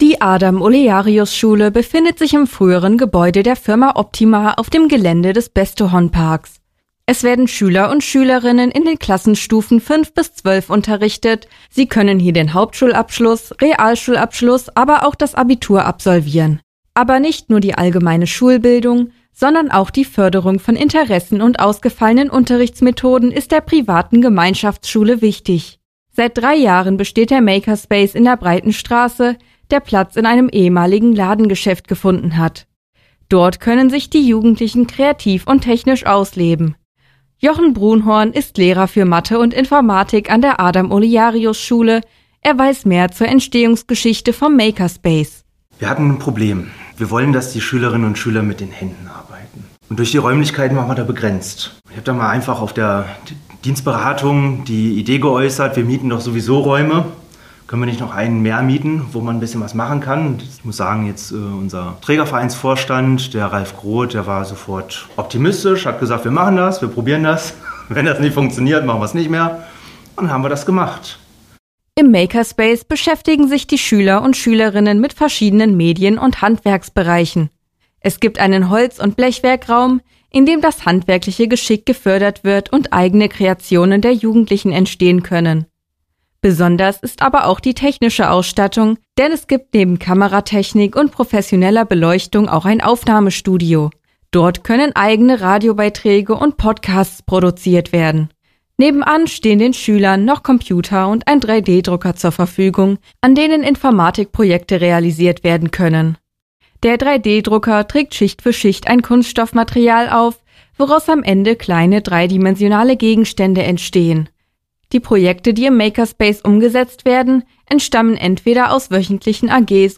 Hörbeitrag vom 7. September 2023